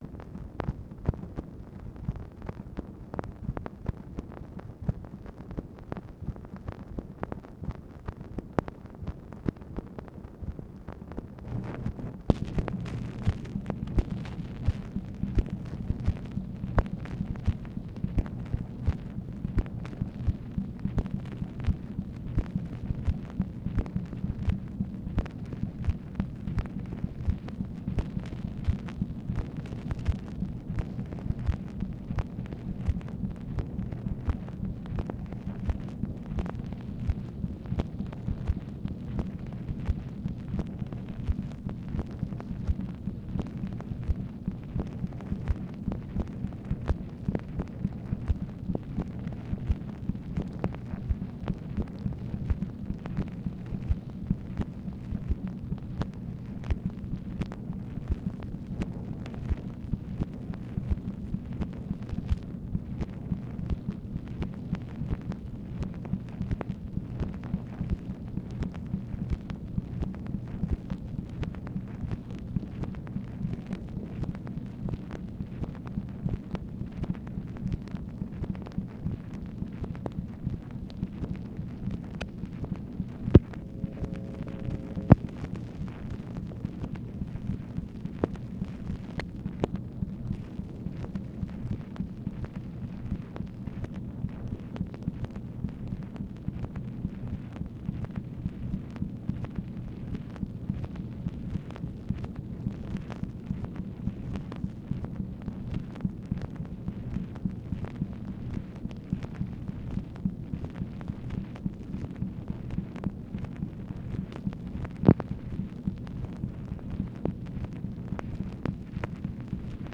MACHINE NOISE, October 22, 1965
Secret White House Tapes | Lyndon B. Johnson Presidency